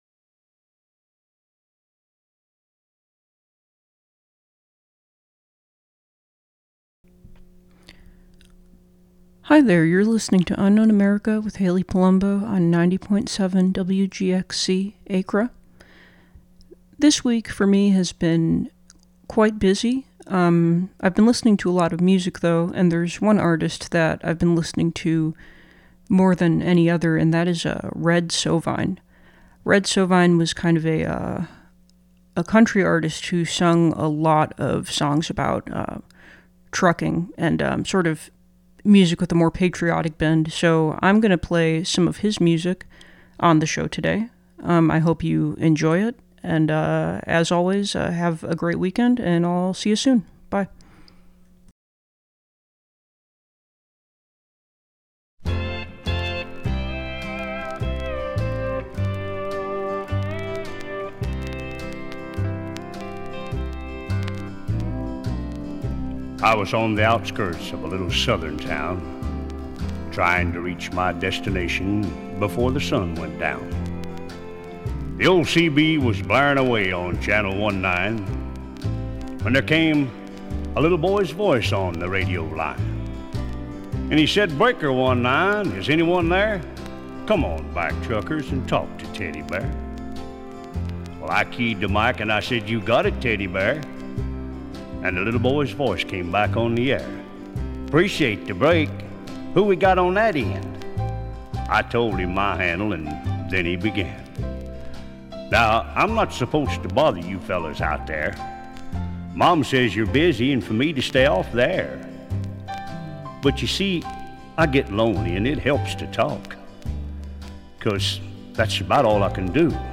4pm This week's episode is all music - a mix of tunes...